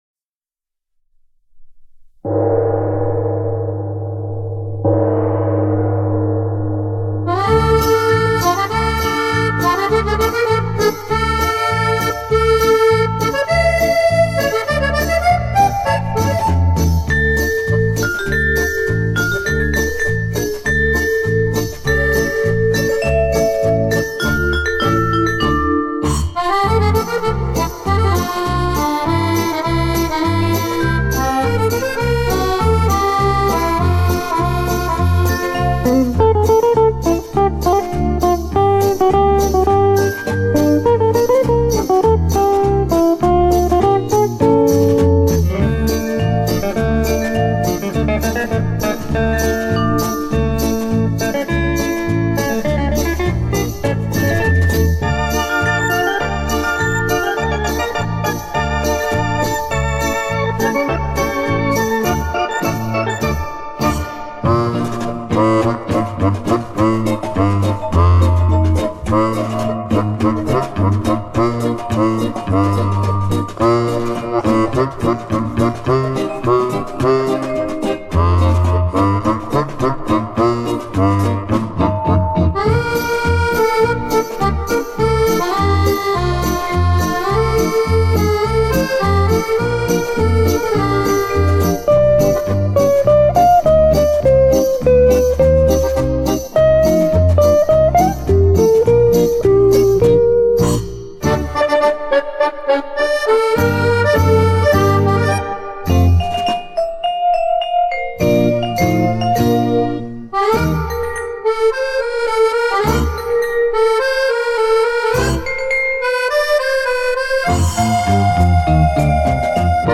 Исполнение начала семидесятых.